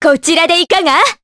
Juno-Vox_Skill4_jp_b.wav